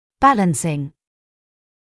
[‘bælənsɪŋ][‘бэлэнсин]балансирующий; уравновешивающий; инговая форма от to balance